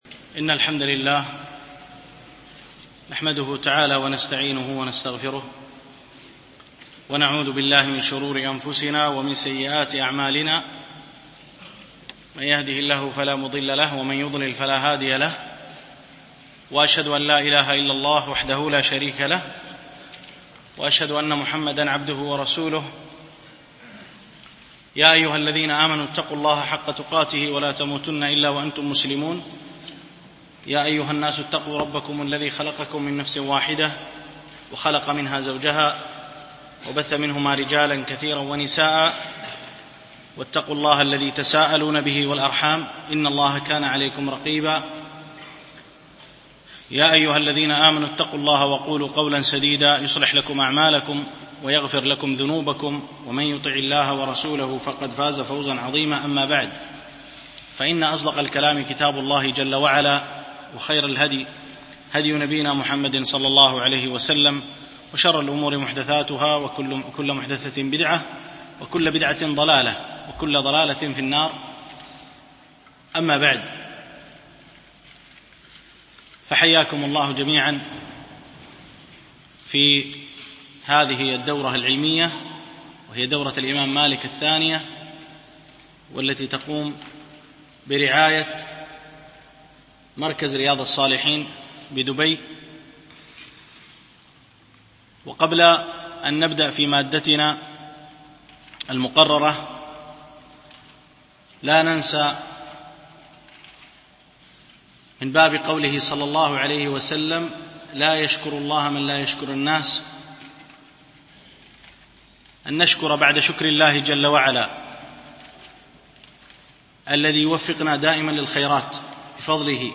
شرح القواعد الحسان في تفسير القرآن ـ الدرس الأول
دروس مسجد عائشة